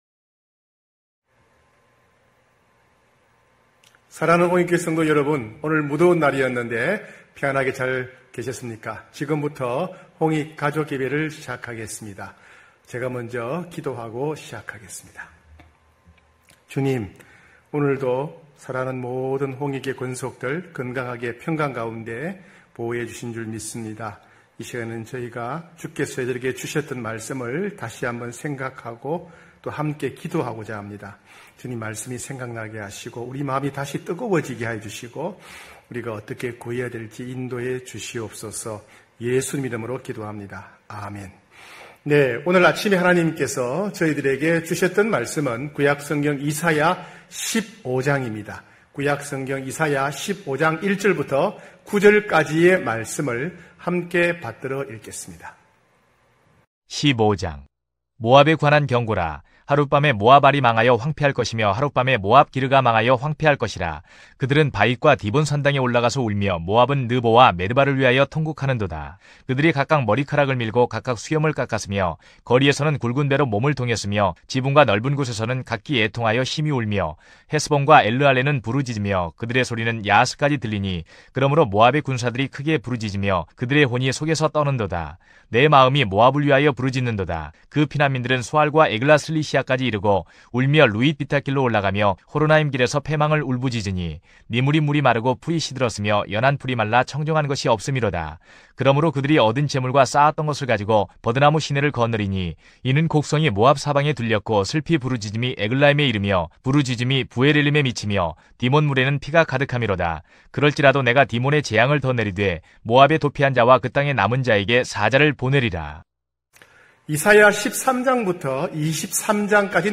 9시홍익가족예배(7월31일).mp3